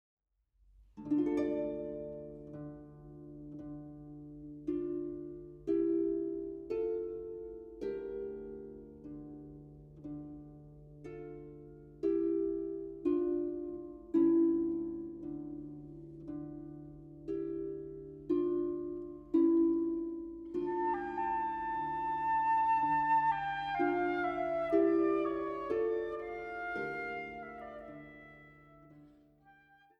Flöte
Oboe und Englischhorn
Klarinette
Fagott
Harfe